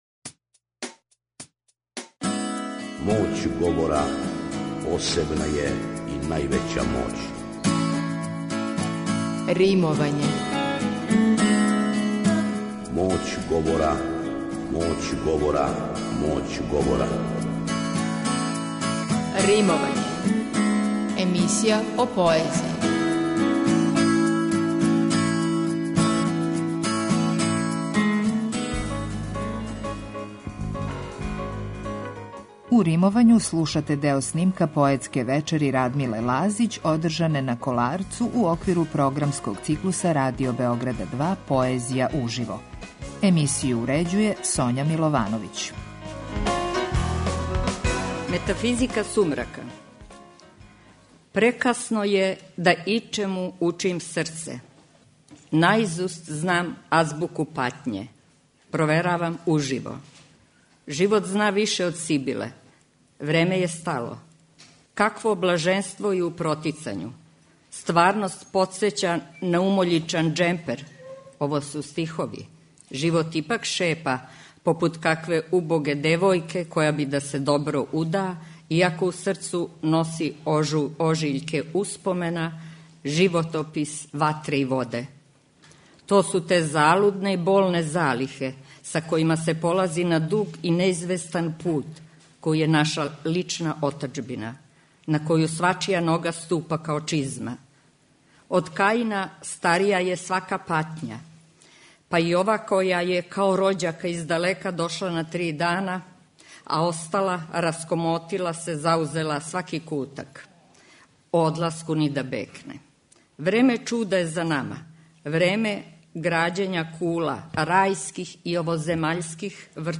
У Римовању , слушате део снимка поетске вечери Радмиле Лазић одржане на Коларцу у оквиру програмског циклуса Радио Београда 2 "Поезија уживо".